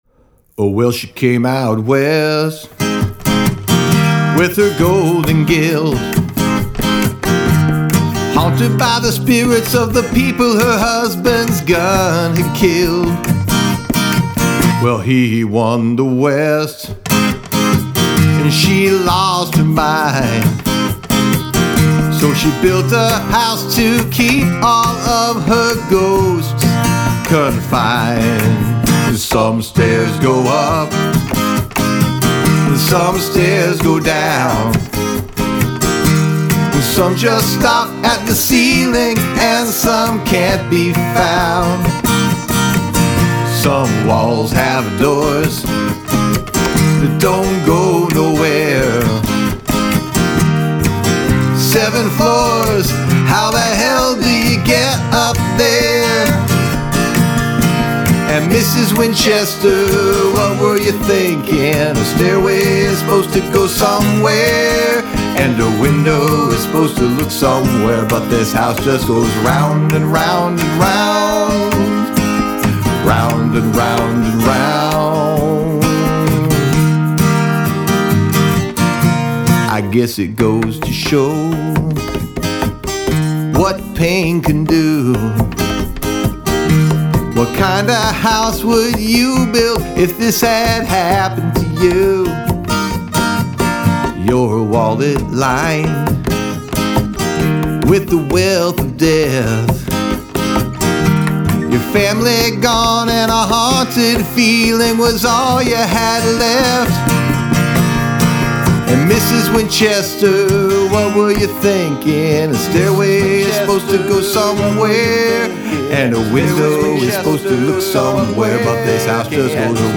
Must include a round.